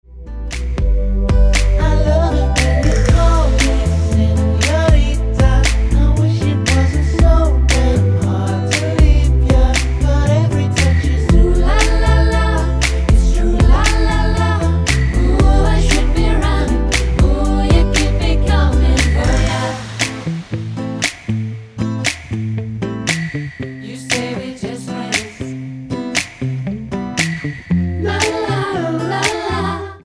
Tonart:Am mit Chor
Die besten Playbacks Instrumentals und Karaoke Versionen .